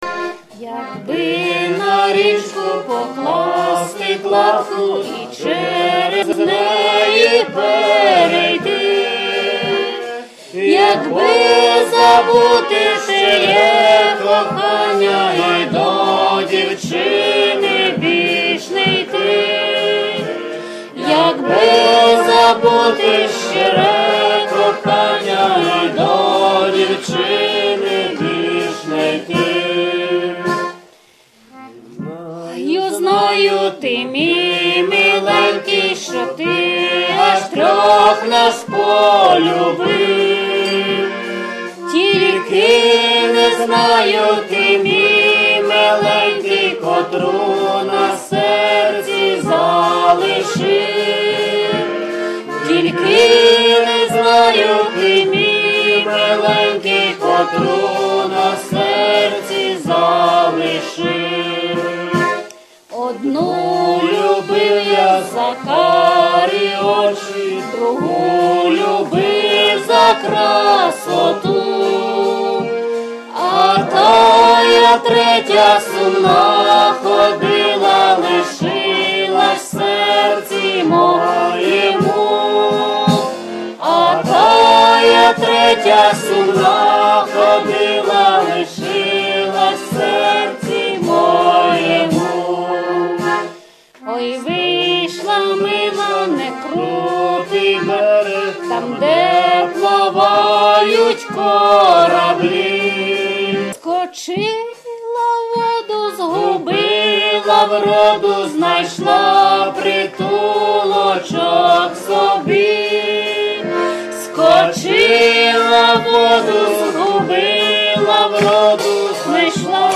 Аматорський аудіозапис (mp3, 128 kbps)